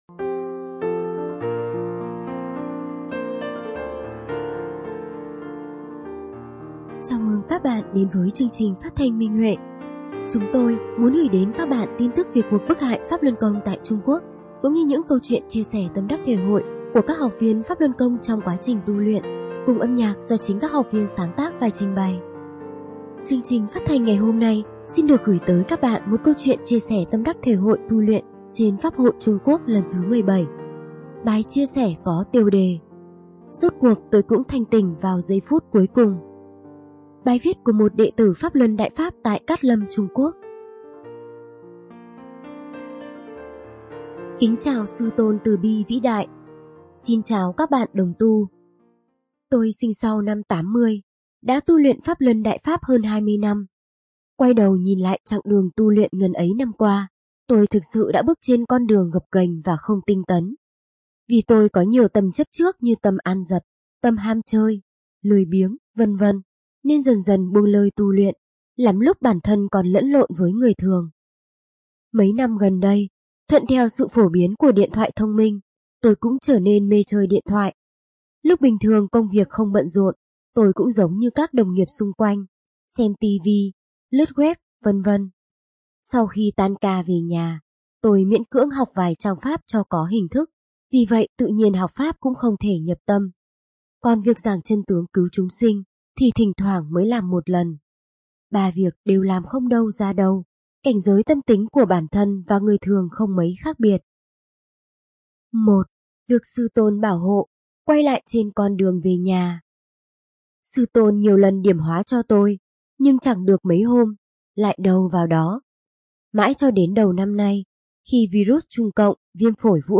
Chúng tôi muốn gửi đến các bạn tin tức về cuộc bức hại PhápLuân Côngtại Trung Quốc cũng như những câu chuyện chia sẻ tâm đắc thể hội của các học viên trong quá trình tu luyện, cùng âm nhạc do chính các học viên sáng tác và trình bày.